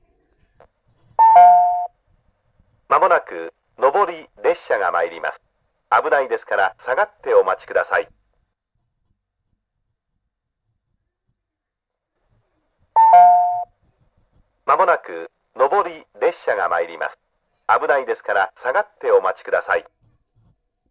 自動放送
上り接近放送
●スピーカー：TOA ホーンスピーカー
●案内放送には適したスピーカーですが、当然音質は良くないです